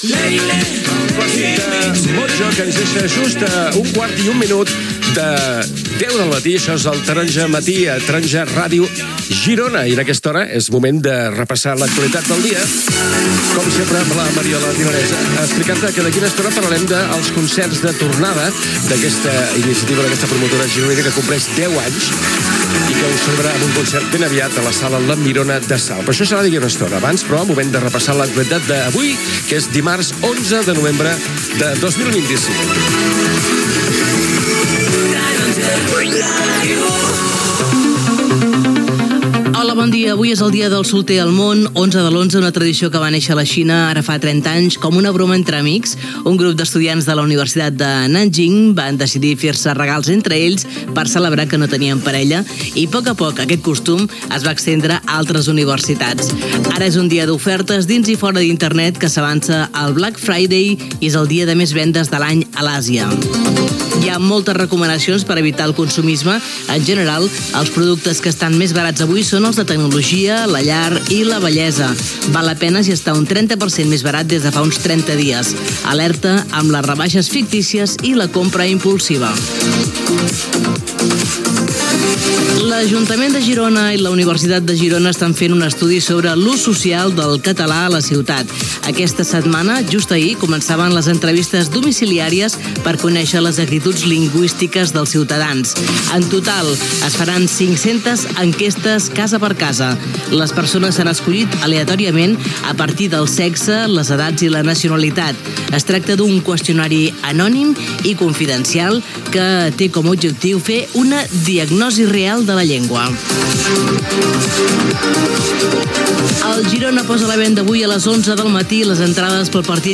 Identificació de la ràdio, actualitat del dia, previsió del temps, indicatiu, tema musical.
Info-entreteniment